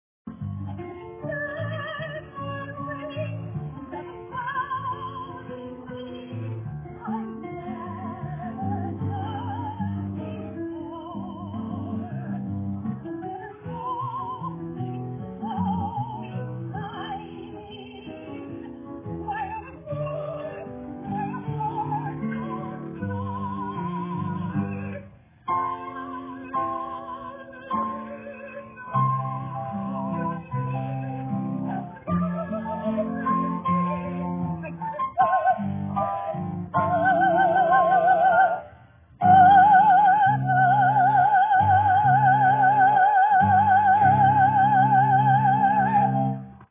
breathtaking voice
during our Welcome Back Reception!